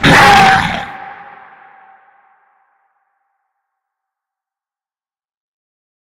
Divergent / mods / Soundscape Overhaul / gamedata / sounds / monsters / lurker / ~hit_1.ogg
~hit_1.ogg